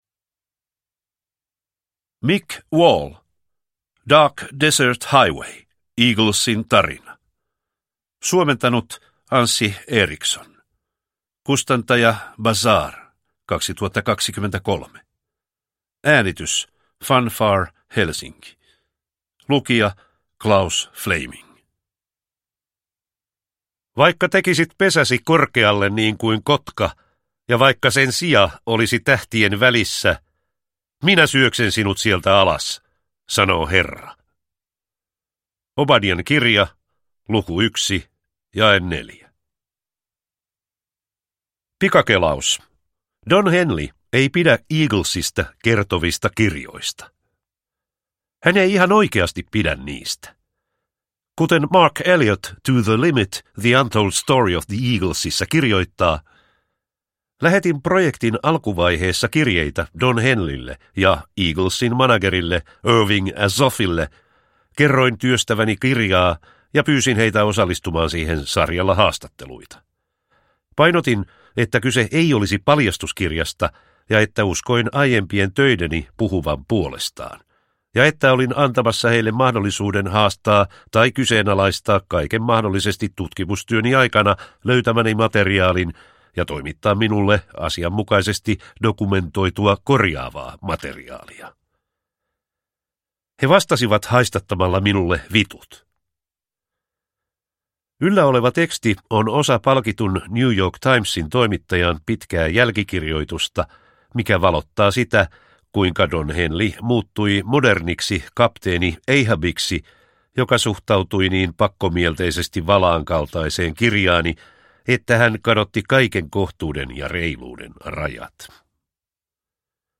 Dark Desert Highway – Ljudbok – Laddas ner